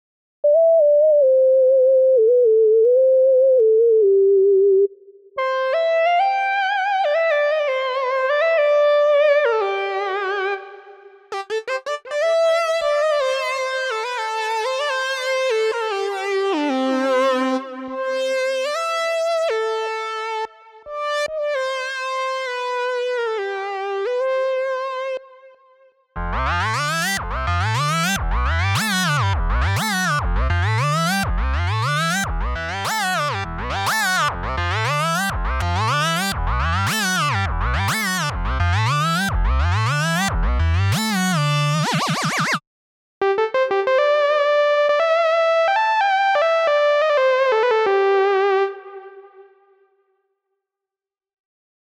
synth-leads1-prev.mp3